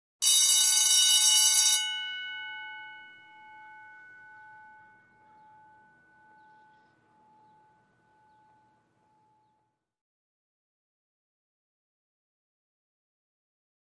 School Bell; Short Ring, Exterior, Close Perspective.